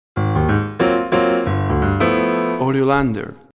Music logo, Piano jazz Fill.
Tempo (BPM) 90